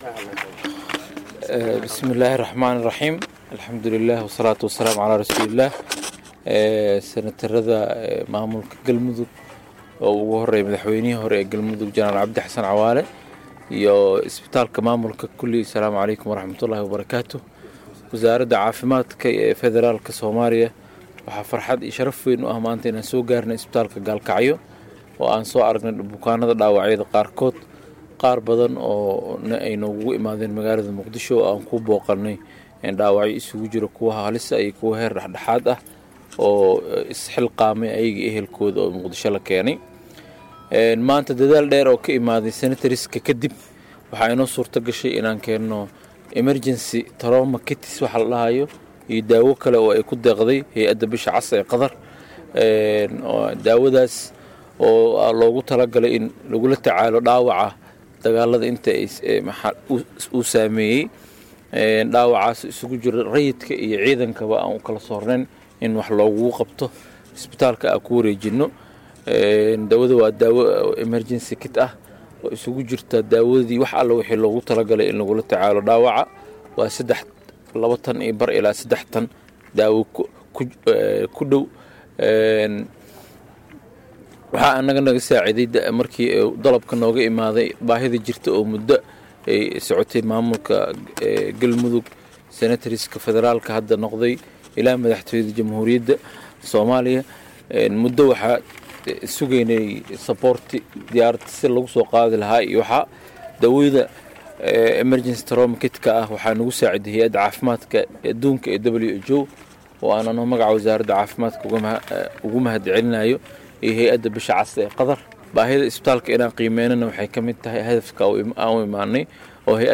Dhageyso Mas’uuliyiin ka socda Wasaaradda Caafimaadka DFS